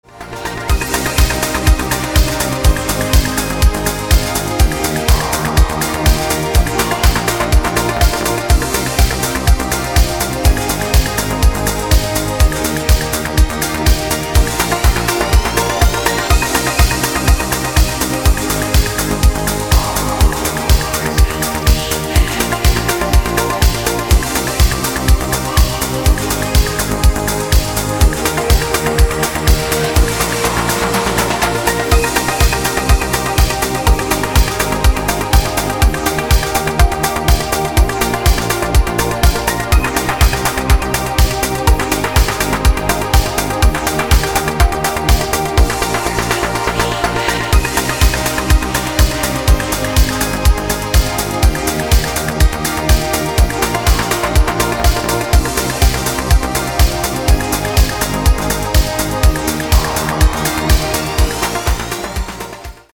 • Качество: 320, Stereo
электронная музыка
красивая мелодия
клавишные
progressive house
Melodic house
шепот